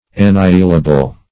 Meaning of annihilable. annihilable synonyms, pronunciation, spelling and more from Free Dictionary.
Annihilable \An*ni"hi*la*ble\, a. Capable of being annihilated.